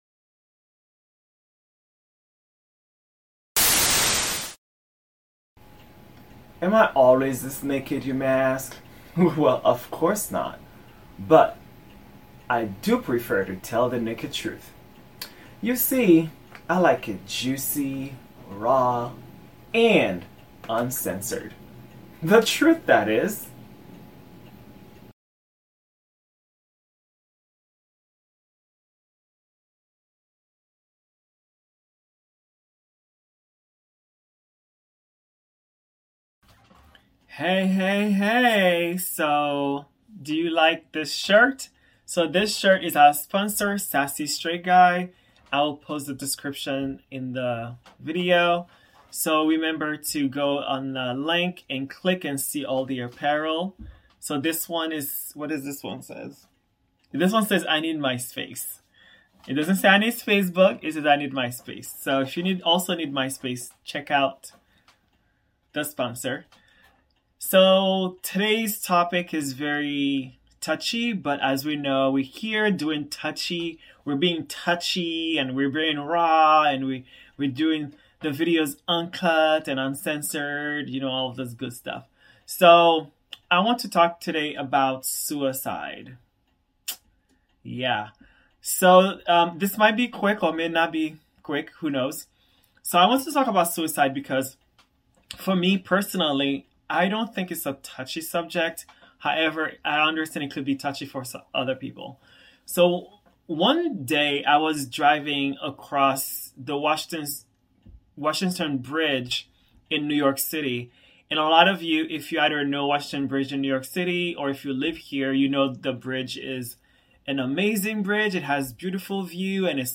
Solo.